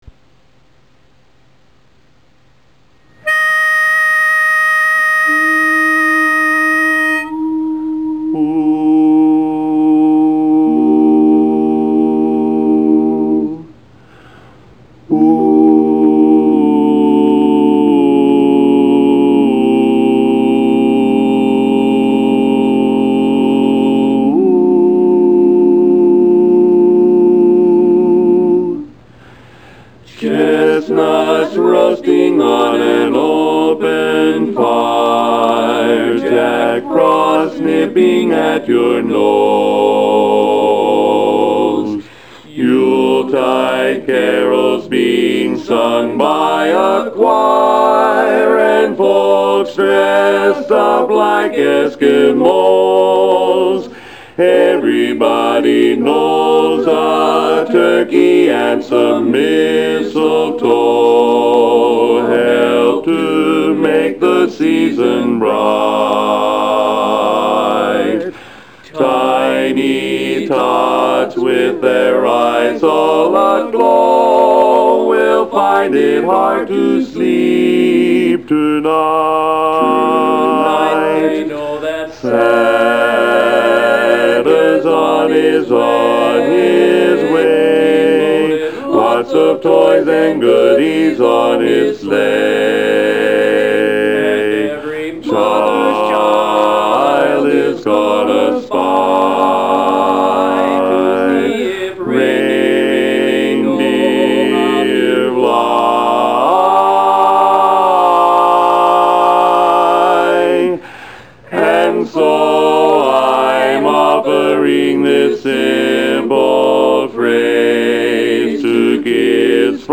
Singing assessment: Presentation assessment: Kanawha Kordsmen (chorus) Active Christmas Songs Up-tempo Barbershop No Not required Not required Download to listen!